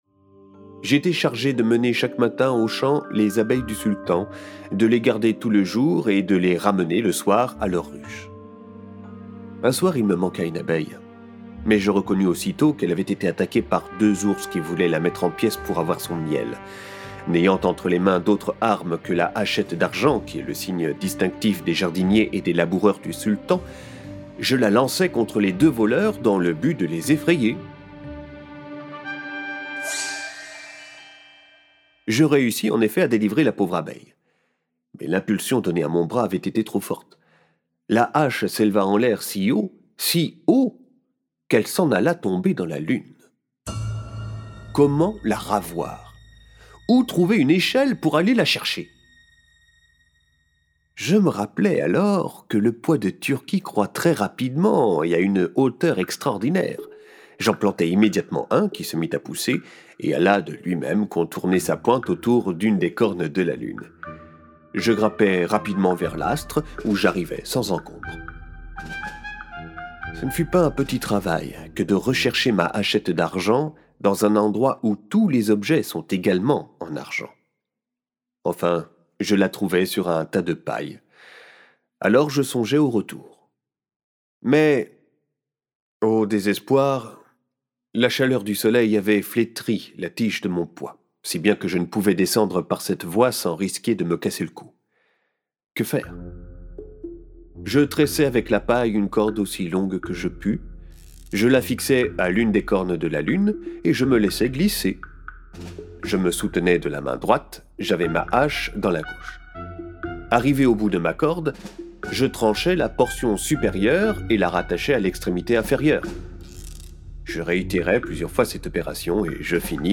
Extrait gratuit - Les aventures remarquables du Baron de Münchhausen de Rudolf Erich Raspe
Récit sonorisé
Musiques et bruitages